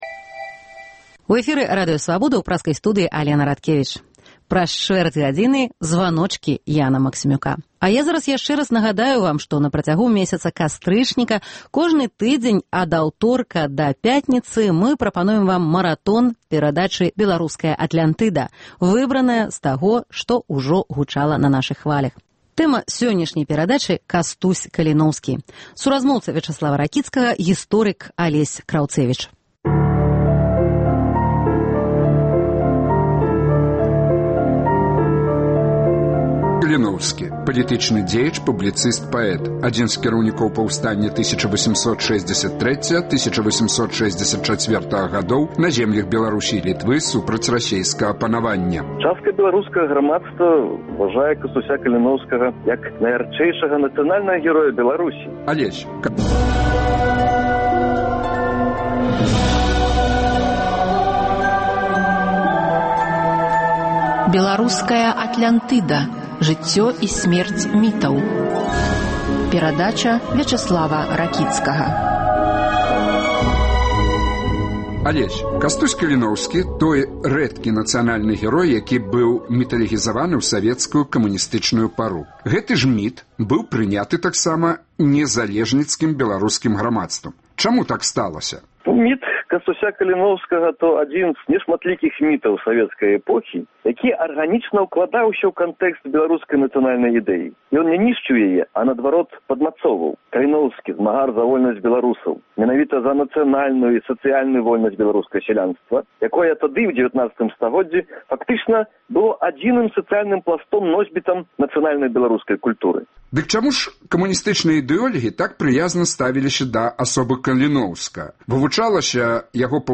Маратон “Атлянтыдаў”, якія прагучалі на хвалях Свабоды ў сэрыі “Жыцьцё і сьмерць мітаў”. Штодня ад аўторка да суботы адмысловыя знаўцы разгадваюць тайны найноўшай беларускай міталёгіі. Тэма сёньняшняга выпуску – Кастусь Каліноўскі.